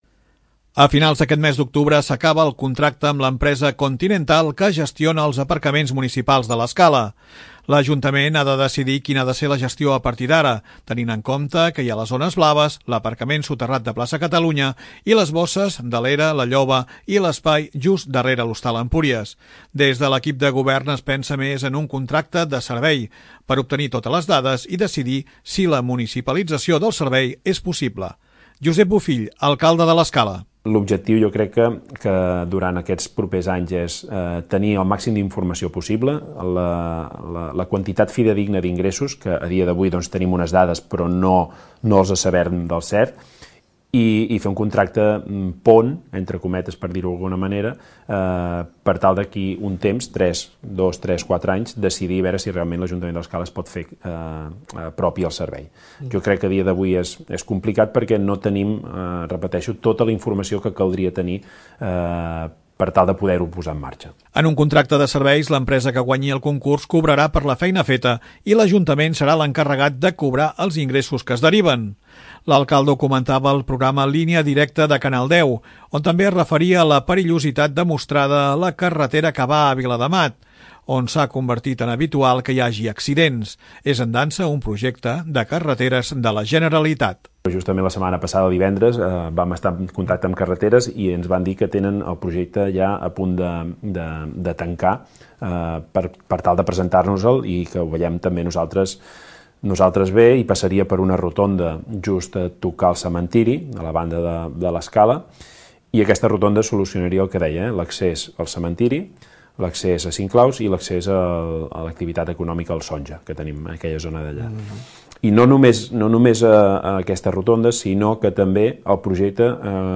L'alcalde, Josep Bofill, ho explicava al programa Línia Directa de Canal 10 Empordà.